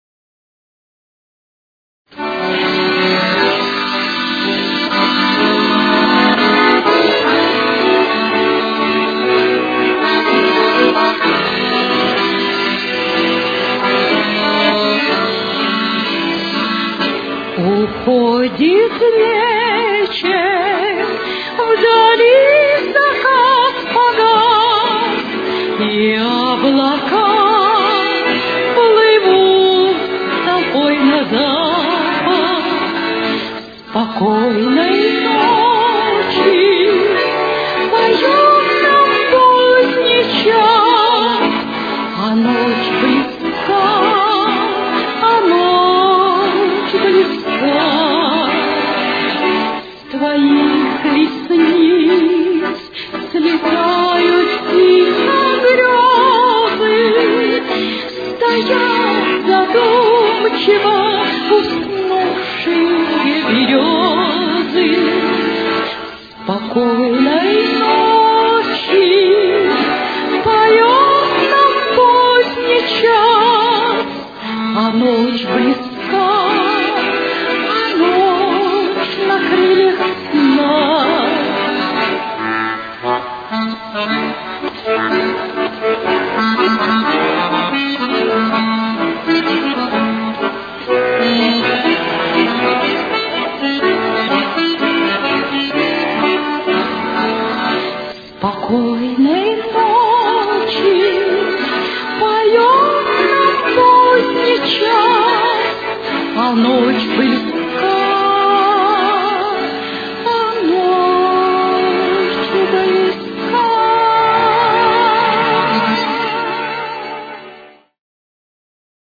с очень низким качеством (16 – 32 кБит/с)
Ре мажор.